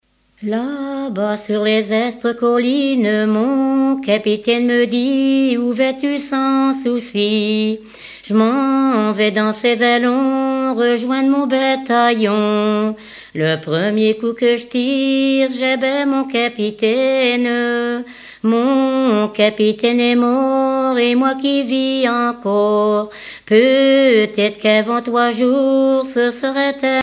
Genre strophique
Chanteuse du pays de Redon